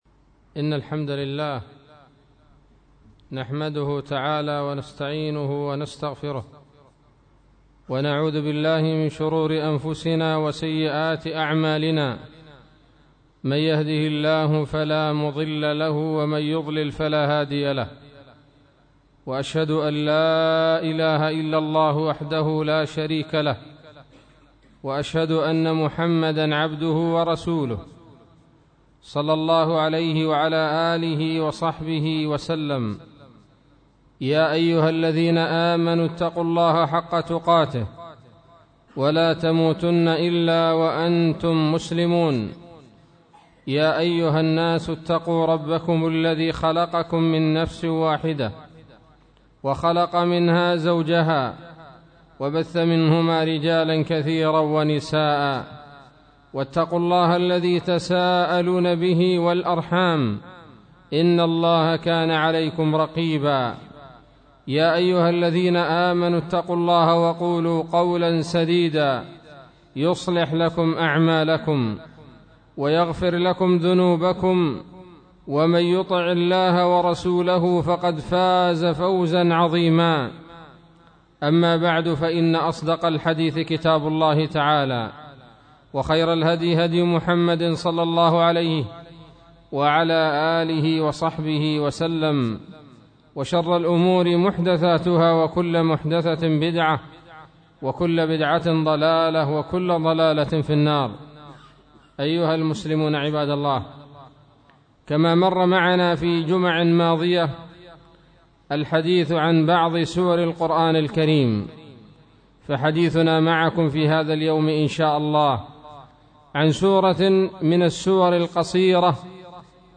خطبة بعنوان : ((تفسير سورة الهمزة)) 12 ربيع الآخر 1437 هـ